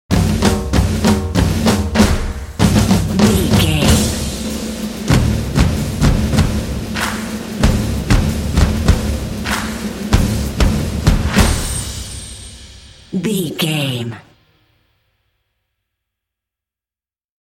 This epic drumline will pump you up for some intense action.
Epic / Action
Atonal
driving
motivational
determined
percussion